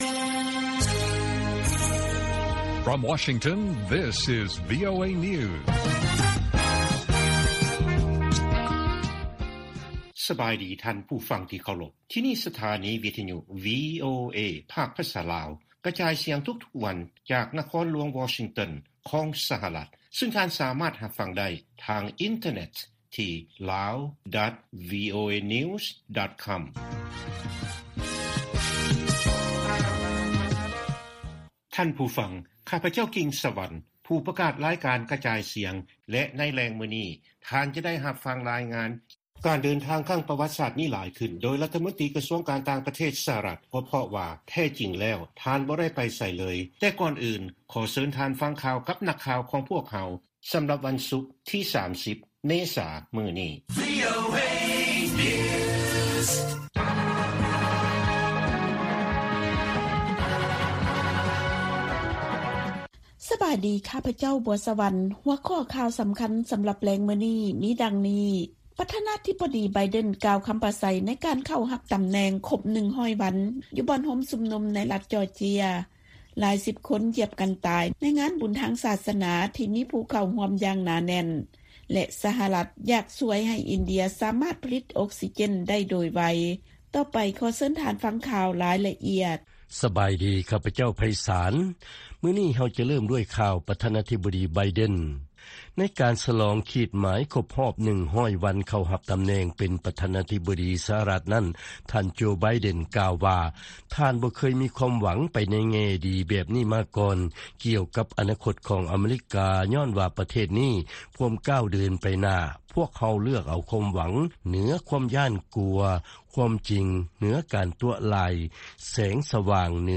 ວີໂອເອພາກພາສາລາວ ກະຈາຍສຽງທຸກໆວັນ. ຫົວຂໍ້ຂ່າວສໍາຄັນໃນມື້ນີ້ມີ: 1) ໄທ ຈະບໍ່ເພີ່ມການນຳເຂົ້າ ພະລັງງານຈົນເຖິງປີ 2026 ເພາະວ່າ ມີພະລັງງານສຳຮອງ ເກີນຄວາມຕ້ອງການ. 2) ຍັງມີແຮງງານລາວ ລັກລອບເຂົ້າໄປຫາວຽກເຮັດ ຢູ່ໄທ ເຖິງຈະມີການເວນຍາມເຄັ່ງຄັດຂຶ້ນ ຍ້ອນໂຄວິດ ກໍຕາມ ແລະຂ່າວສໍາຄັນອື່ນໆອີກ.